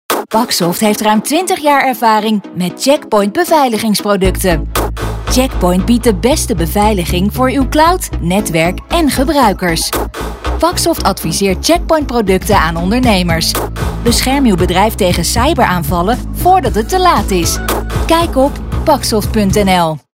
Radio Reclame